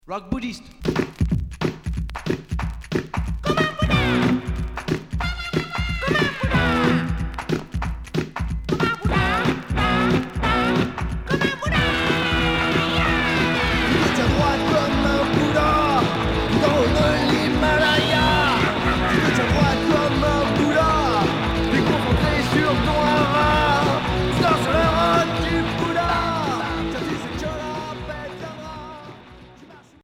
Punk Alternatif